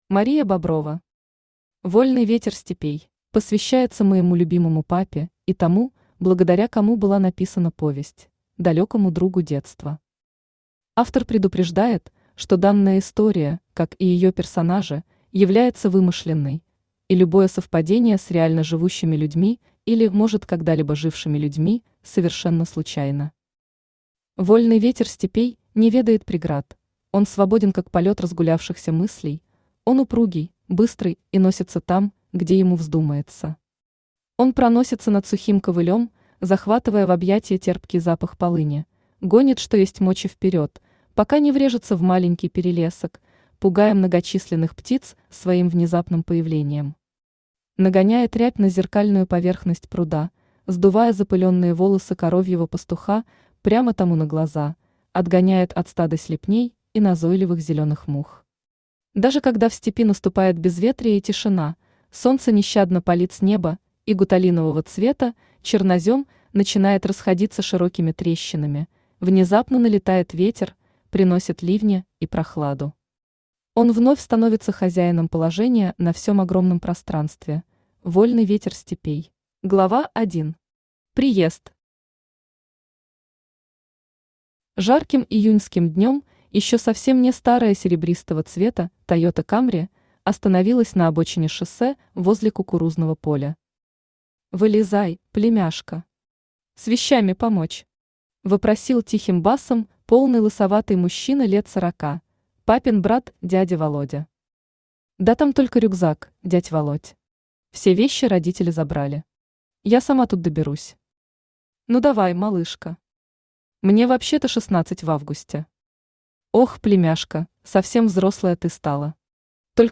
Аудиокнига Вольный ветер степей | Библиотека аудиокниг
Aудиокнига Вольный ветер степей Автор Мария Николаевна Боброва Читает аудиокнигу Авточтец ЛитРес.